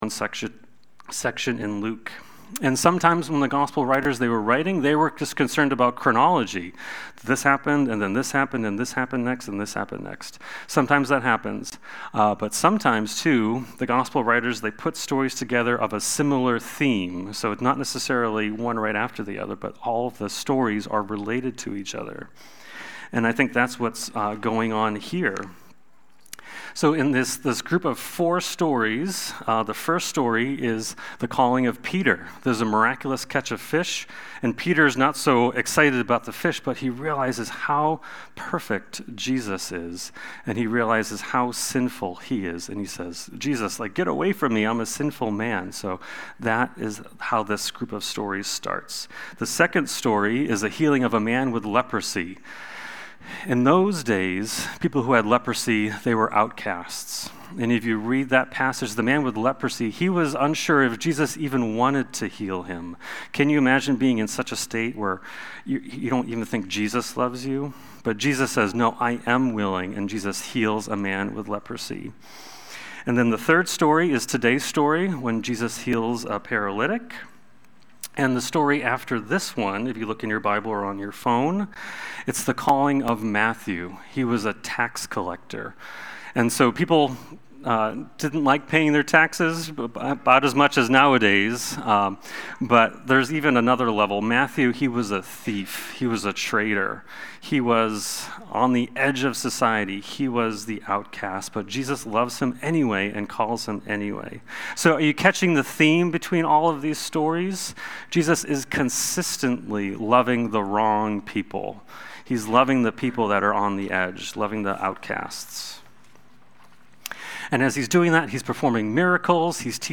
Watch the replay or listen to the sermon.
Sunday-Worship-main-8325.mp3